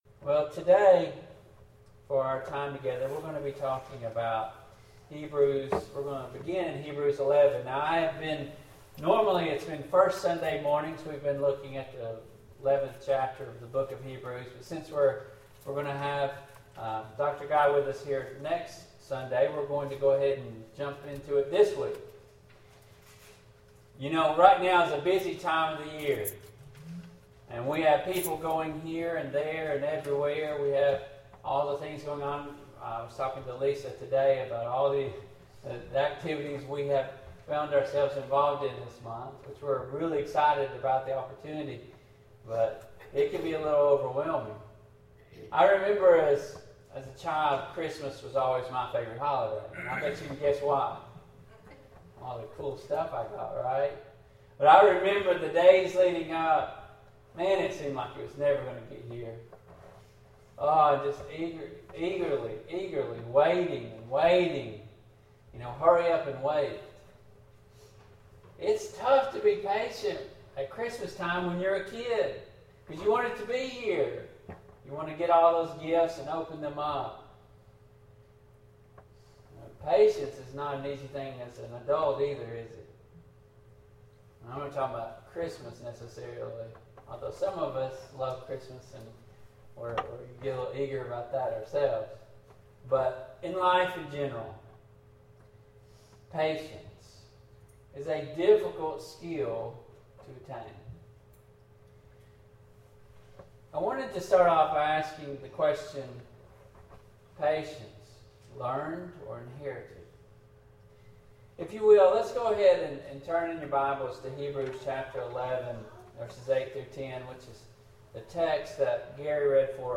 Passage: Hebrews 11:8-10; Hebrews 6:15; James 1:2-4; II Peter 1:5-8; Galatians 5:22-23; Proverbs 14:29; Proverbs 15:18; Matthew 5:23-24; Matthew 18:15; Luke 23:34; Acts 15:37-40; Genesis 6:5-7; Romans 8:28; Joshua 24:15; II Peter 3:9 Service Type: AM Worship